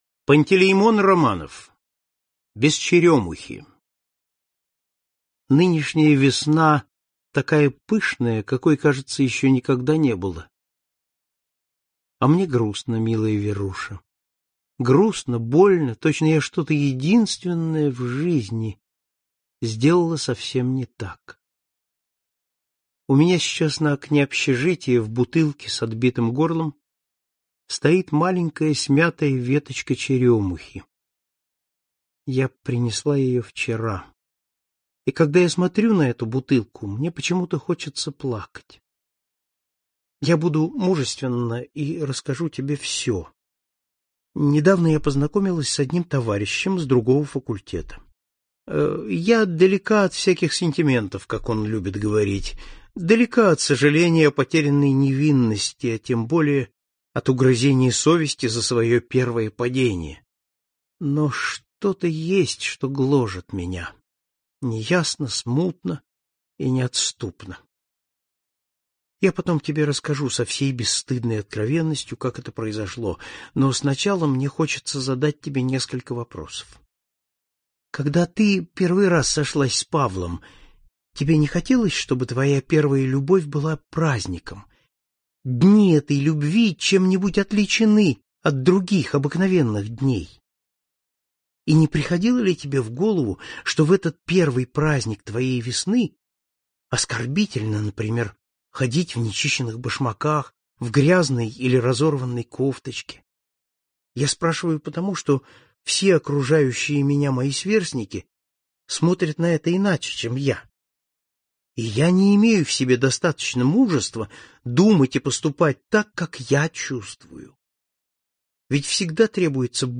Аудиокнига Классика русского рассказа № 6 | Библиотека аудиокниг